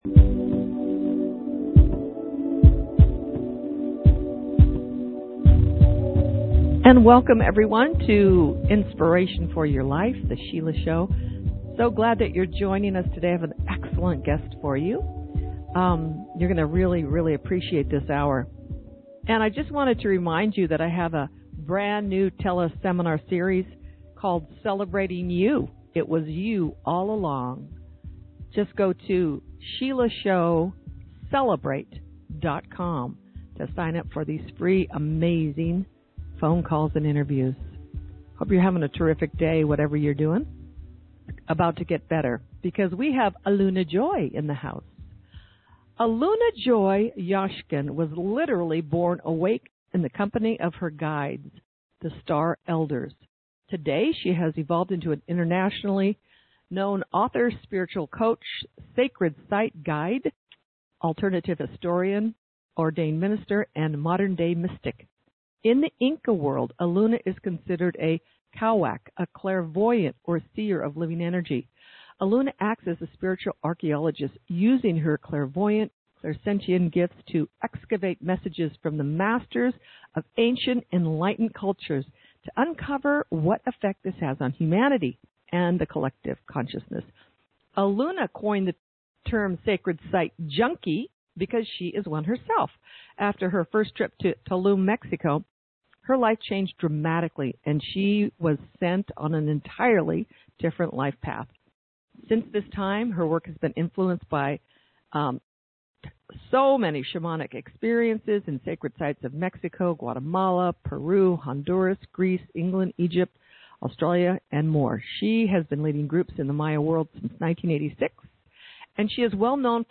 This is a riveting, informative interview that will answer lots of questions you have about "this revolutionary time," describing common symptoms we're all going through from dizziness, neck pain, vertigo, nausea, falling, sleeplessness, and more! Also how to get support!